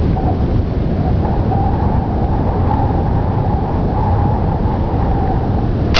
Windhowl
WindHowl.wav